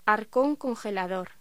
Locución: Arcón congelador